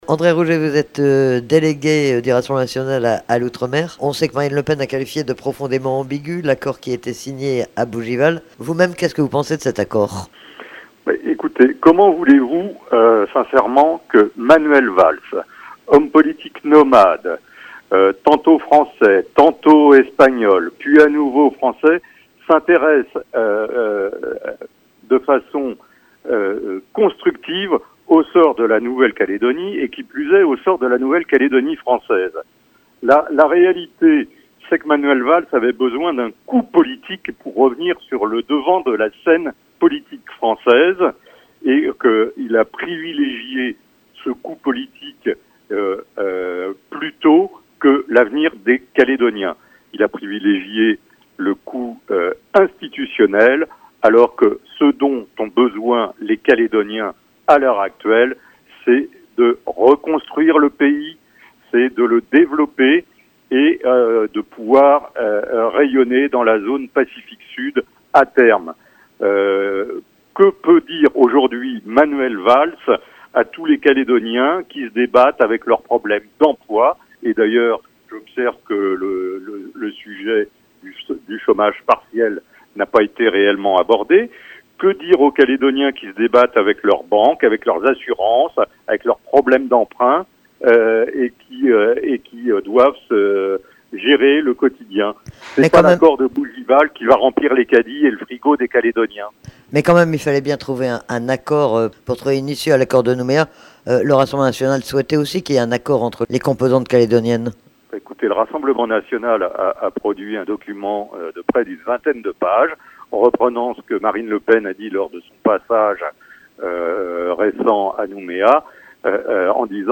C'est le député européen André Rougé, délégué national à l’Outremer du Rassemblement national, qui était notre Invité du Matin, à 7h30. Après que Marine Le Pen ait qualifié de profondément ambigu l'accord de Bougival, il nous a accordé une interview dans laquelle il précise la position du Rassemblement national et les critiques qu'il formule envers l'accord de Bougival.